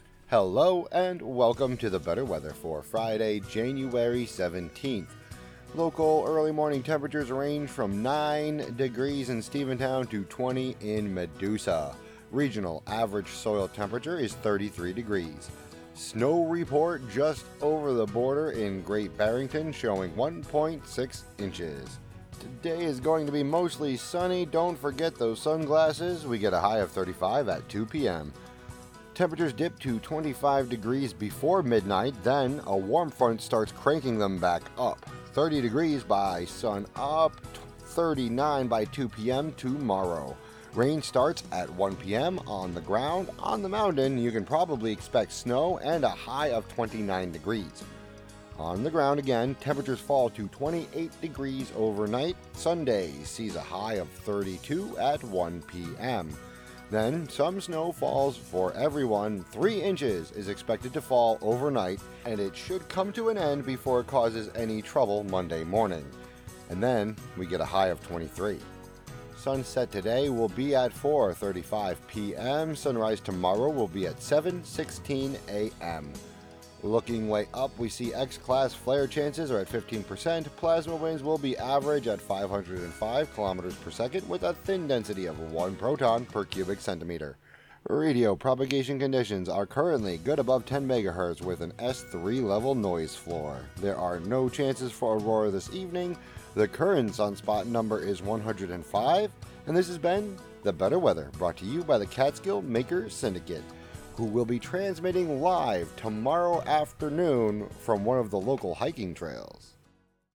broadcasts